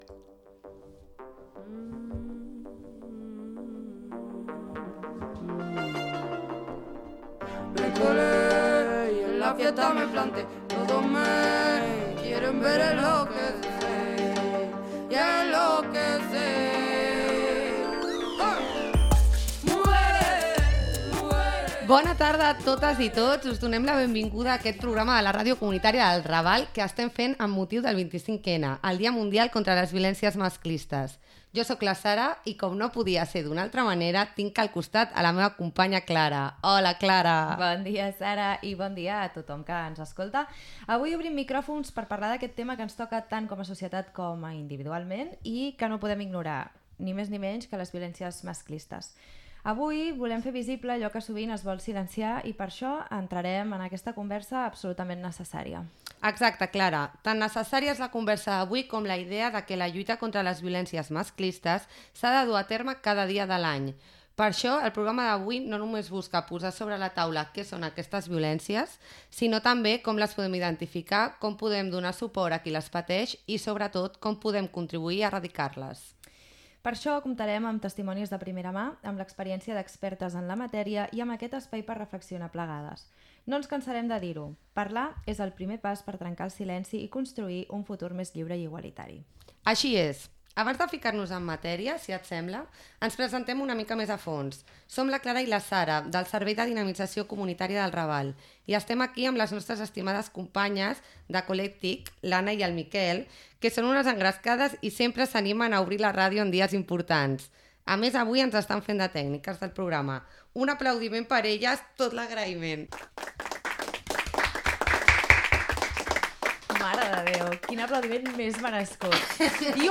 Dia mundial contra la violència masclista. Presentació del programa fet per dues integrants del Servei de Dinamització Comunitària del Raval i Colectic.